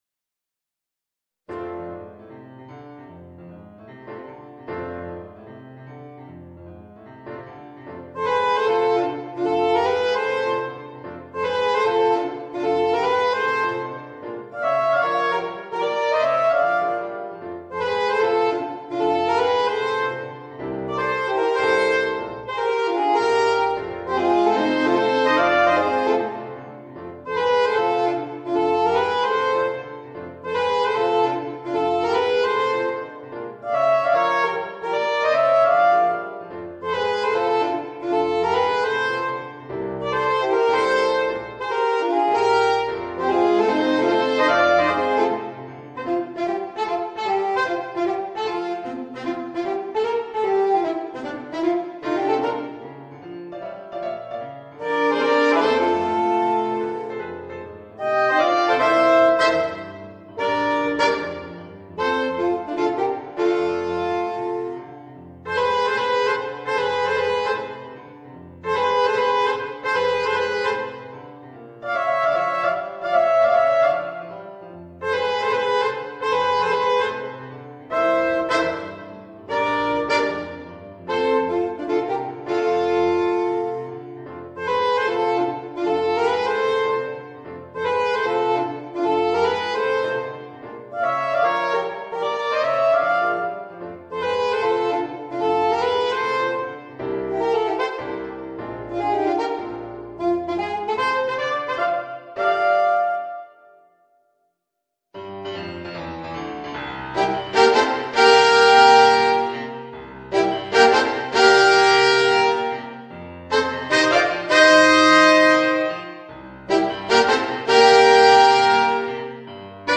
Voicing: 2 Alto Saxophones and Piano